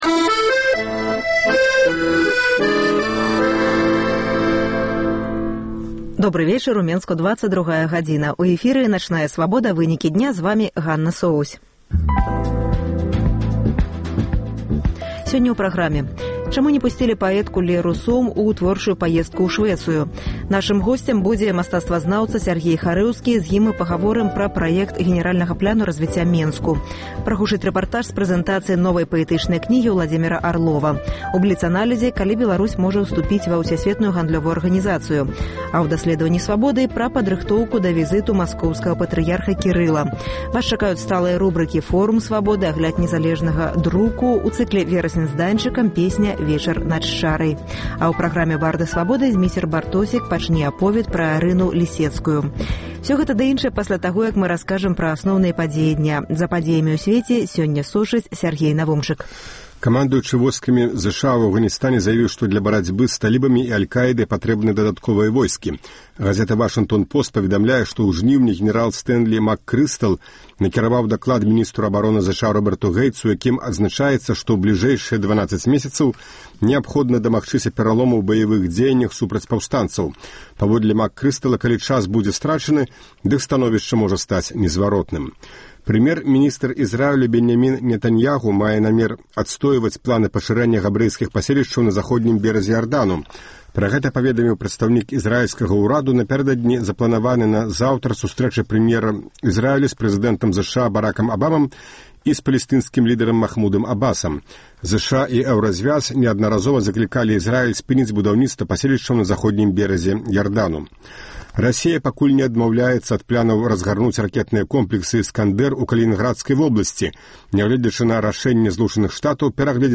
- Агляд СМІ.
- Госьць у жывым эфіры